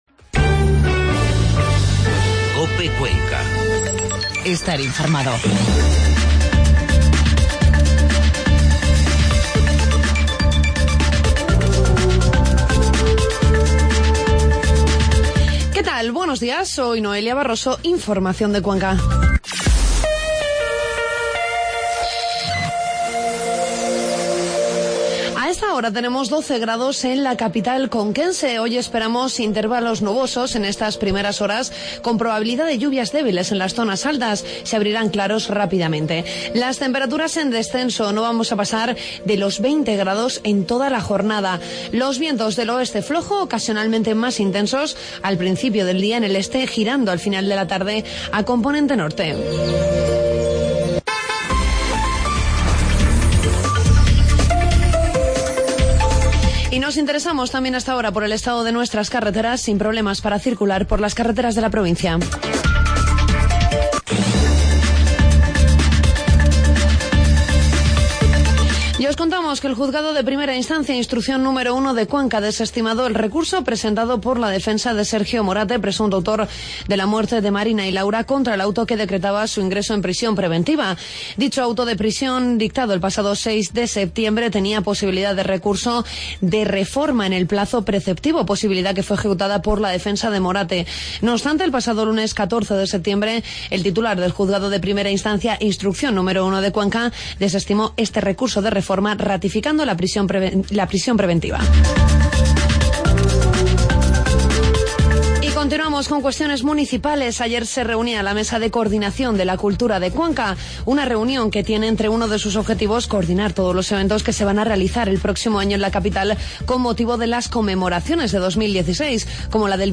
Informativo matinal jueves 17 de septiembre